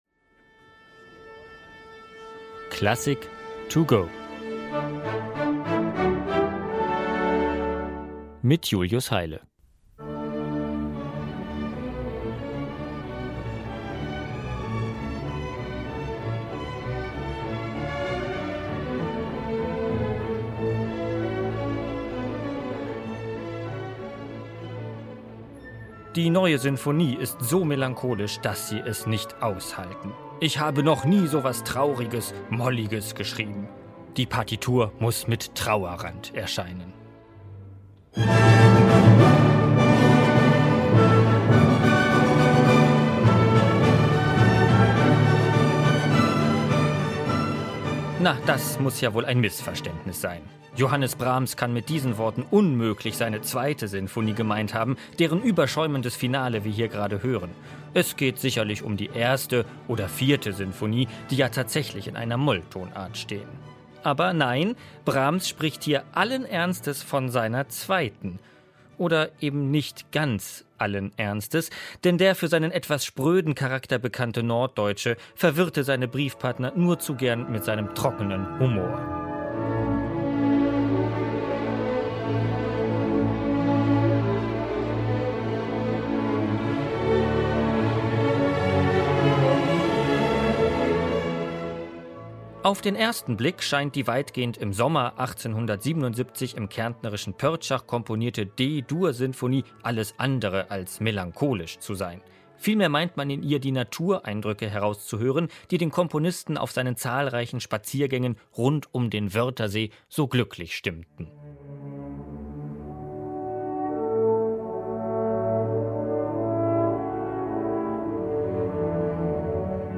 Konzerteinführung für unterwegs.